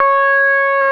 SYN SOLOSYN2.wav